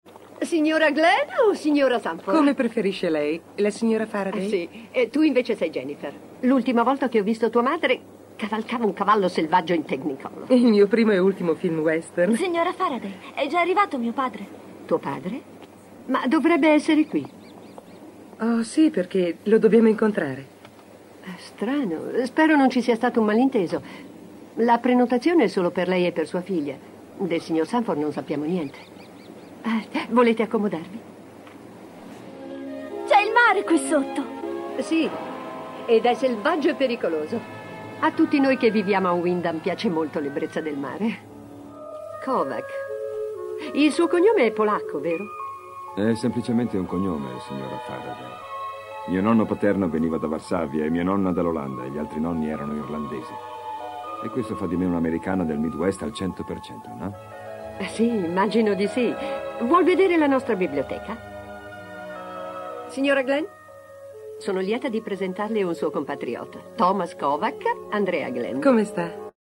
nel film TV "Una testa di lupo mozzata", in cui doppia Rachel Roberts.